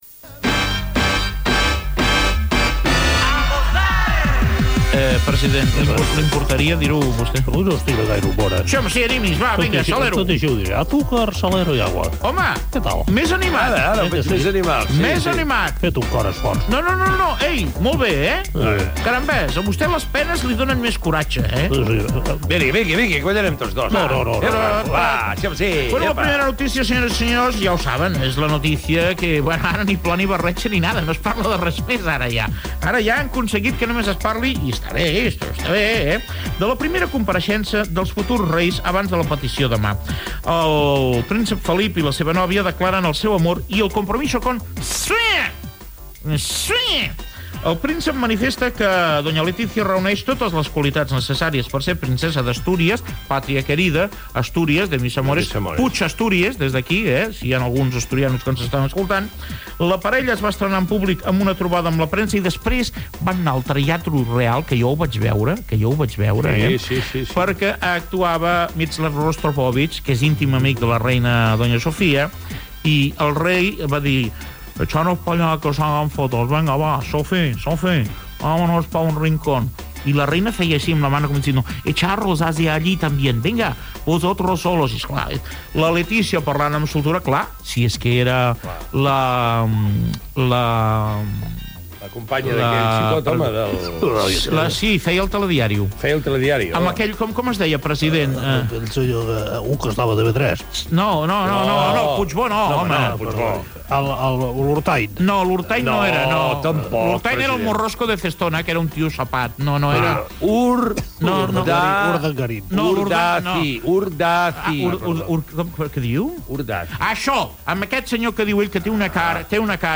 Comentaris sobre la primera aparició pública del príncep d'Astúries Felipe de Borbón i la seva promesa Letizia Ortiz, adreça de COM Ràdio a Internet Gènere radiofònic Entreteniment